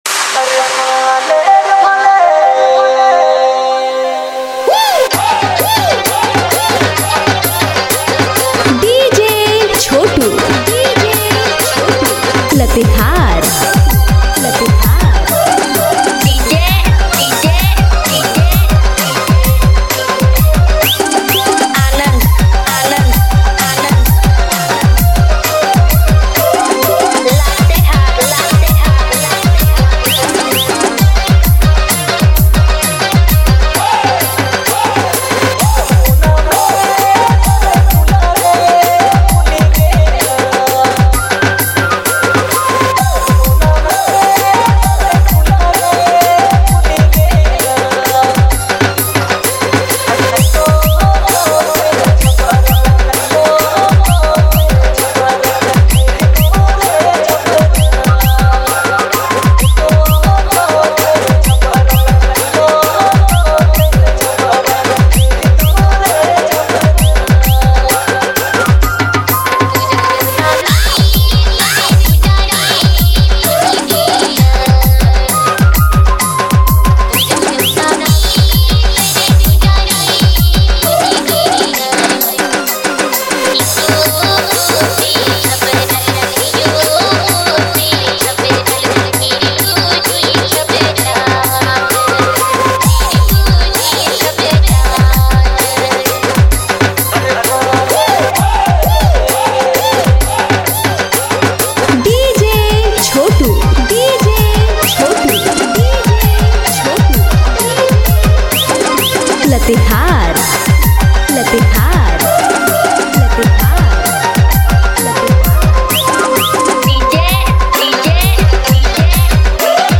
Listen to the vibrant Nagpuri remix